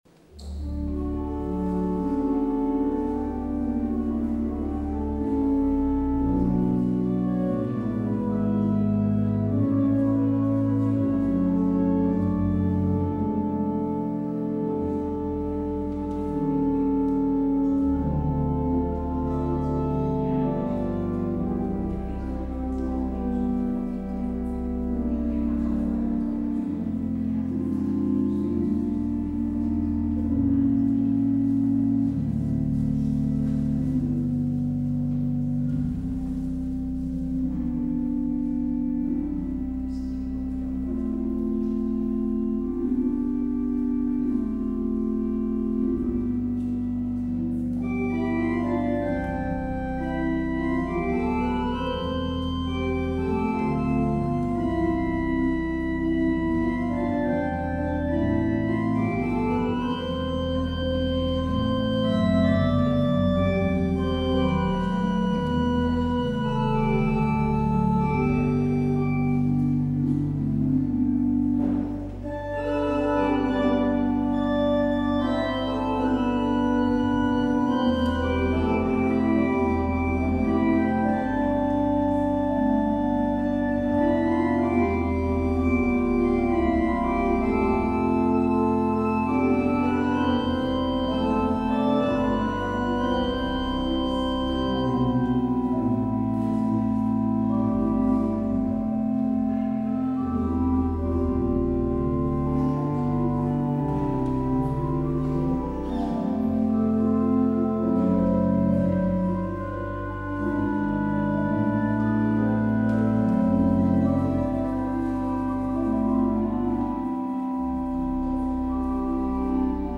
 Luister deze kerkdienst hier terug: Alle-Dag-Kerk 31 oktober 2023 Alle-Dag-Kerk https
Als slotlied hoort u HH 391 vers 1, 2 en 3.